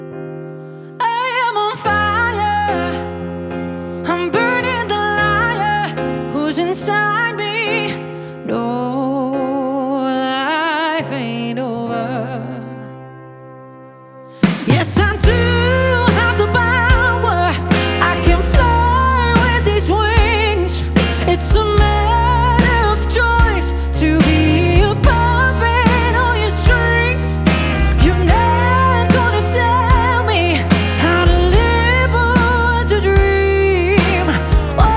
Rock Pop
Жанр: Поп музыка / Рок